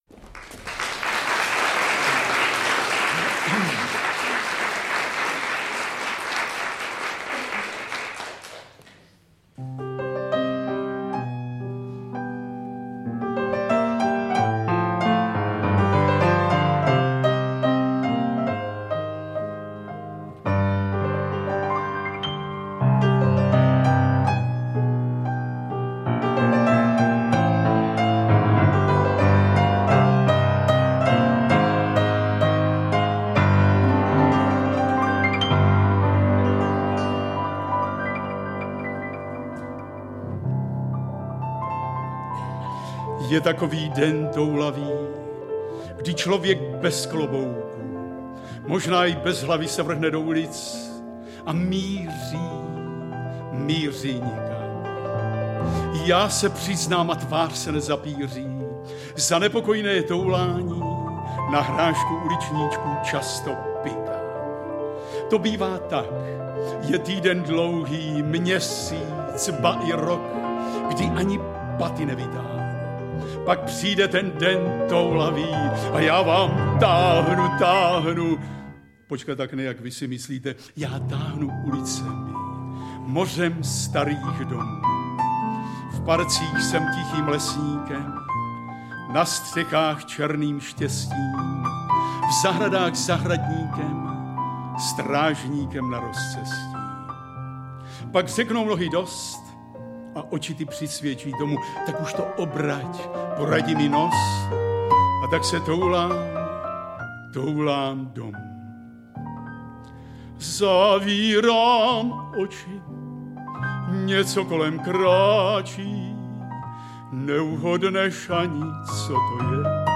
Interpret:  Josef Bek